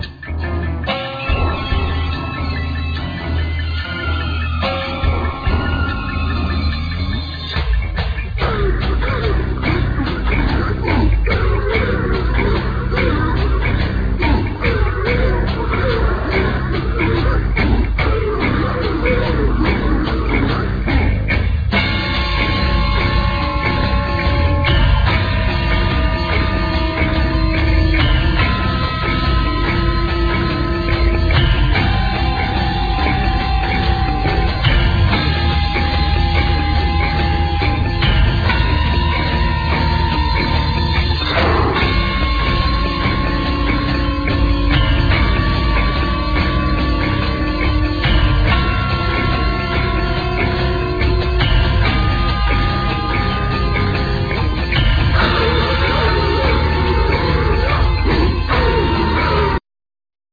Guitar,Mandola,Voices
Drums,Percussion,Voices
Bass,Zurna,Saz,Flutes,Vocals
Text Performance
Cello